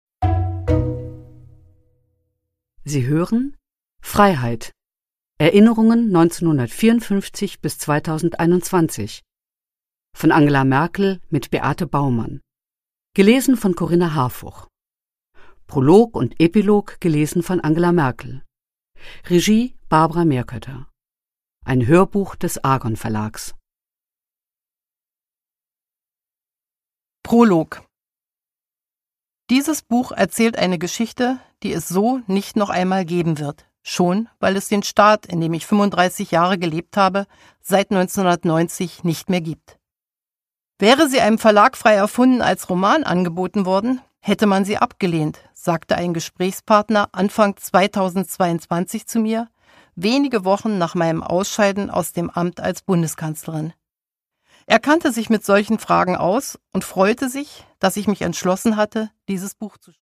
Produkttyp: Hörbuch-Download
Gelesen von: Corinna Harfouch, Angela Merkel